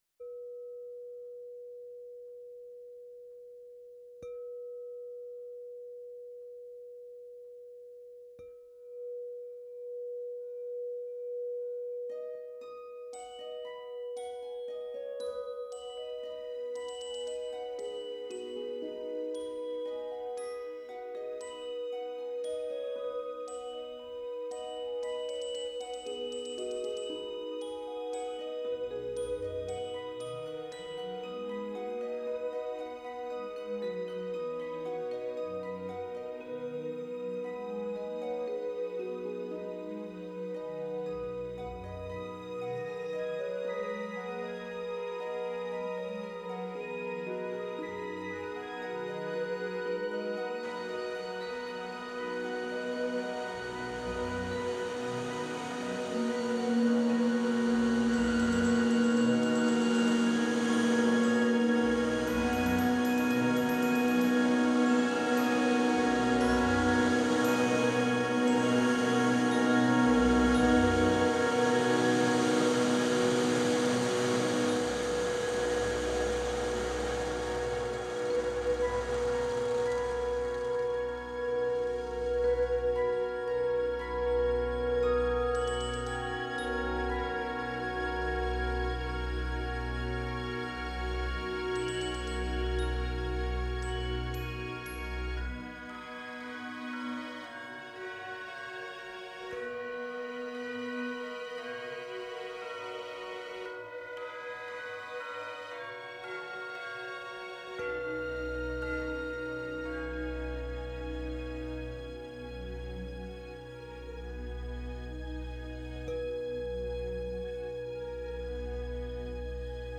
is in the key of B.